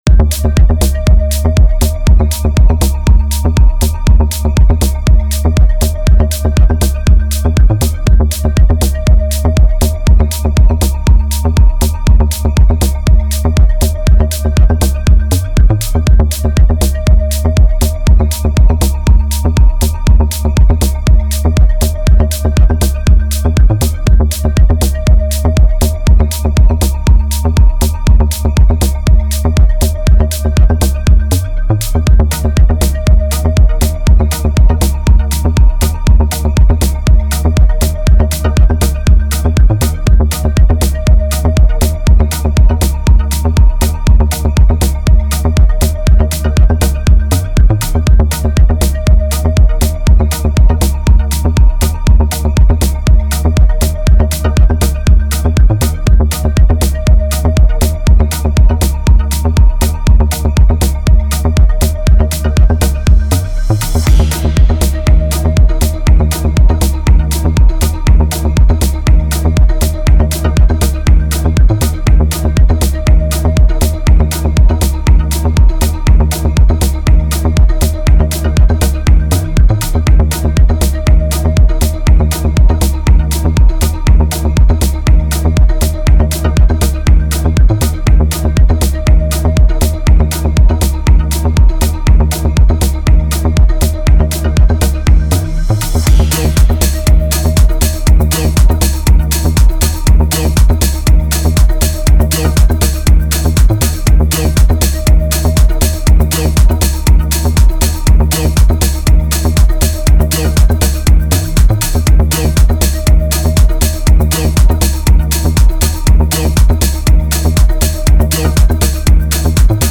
Old Hindi DJ Remix Songs